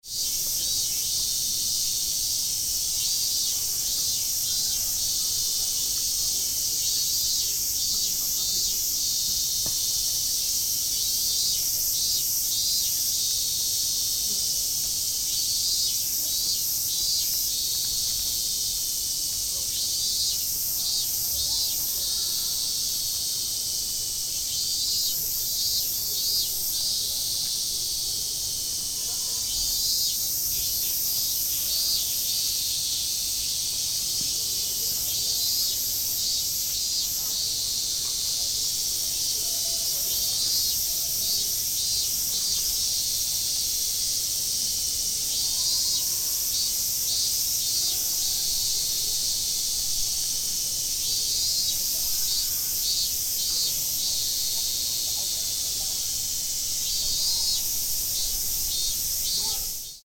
Fukushima University in summer is famous for the loud songs of cicadas. Cicadas were singing so loud and annoyingly this summer, as usual.
Therefore, I recorded this soundscape at this point.